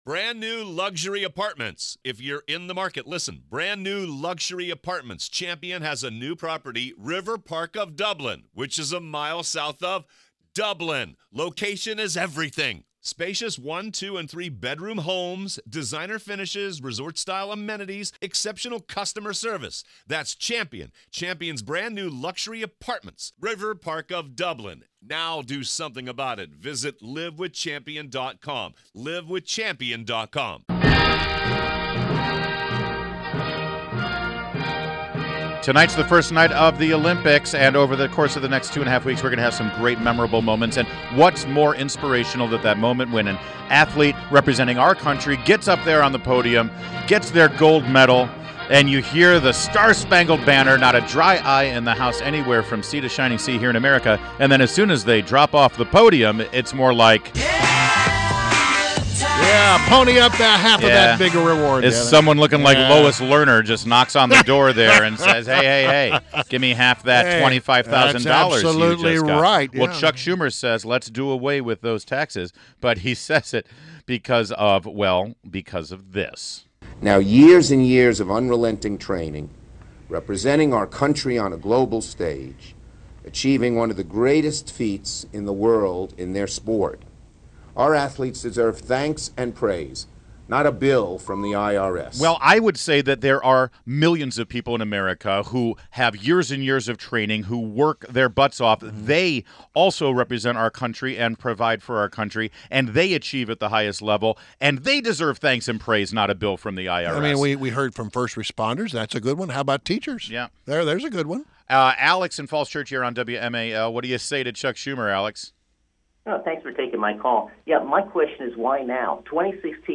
WMAL Interview - Redskins legend JOE THEISMANN - 08.05.16